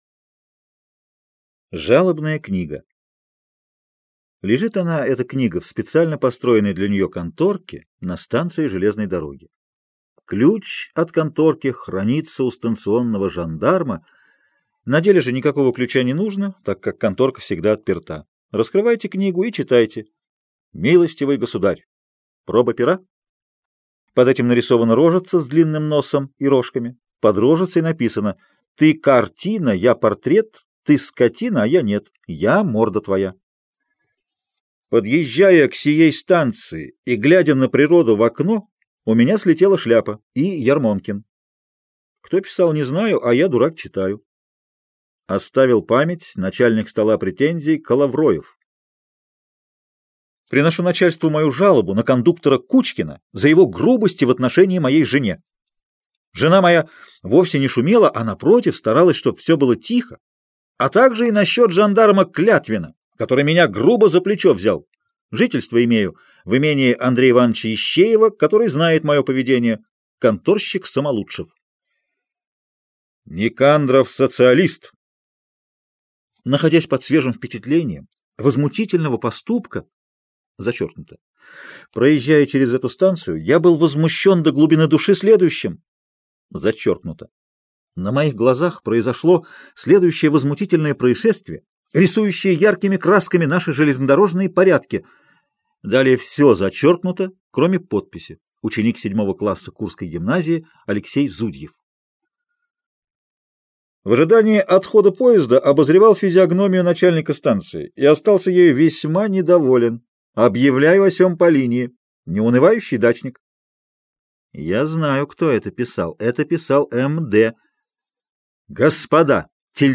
Жалобная книга - аудио рассказ Чехова А.П. Небольшой рассказ, в котором автор высмеивает человеческую глупость.